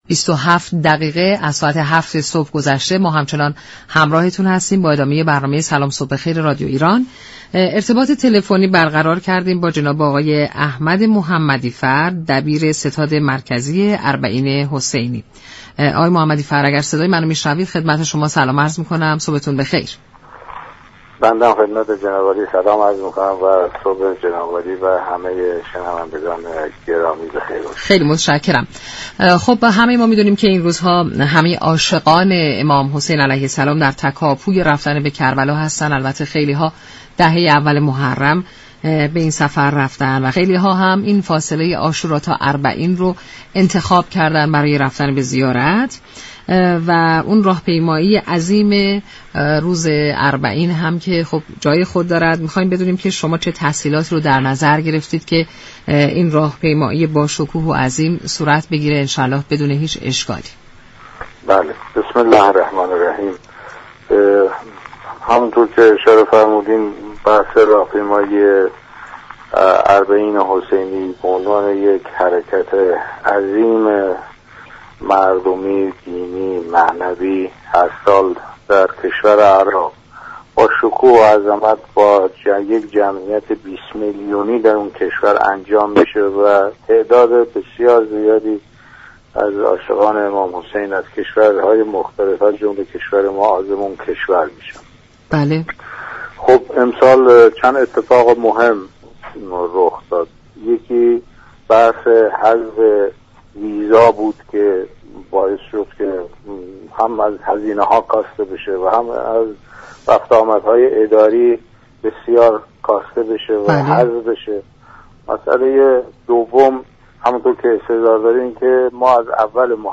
دبیر ستاد مركزی اربعین حسینی در گفت و گو با رادیو ایران گفت: از آغاز محرم تاكنون حدود 300 هزار نفر از مردم بدون هیچگونه مشكلی از مرزهای ایران خارج شده اند.